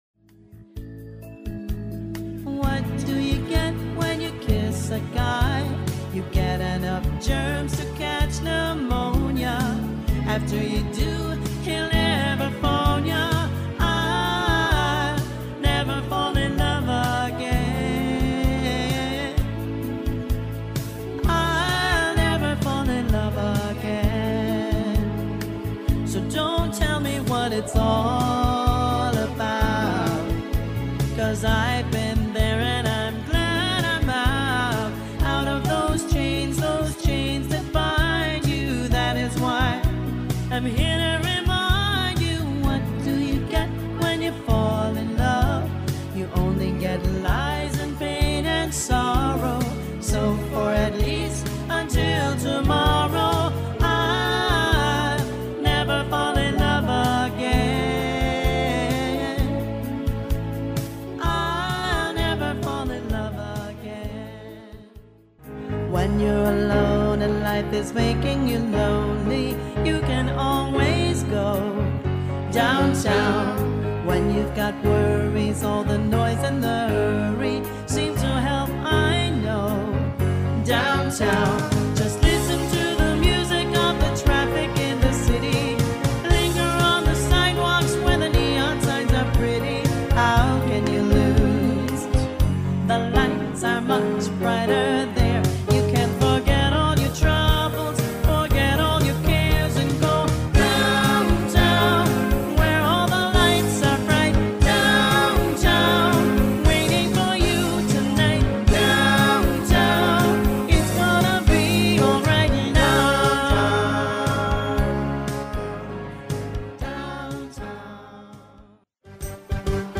1. 60's 70's Pop
The mix may not always be perfect, you may here some
minor distortion, you will here some background noise.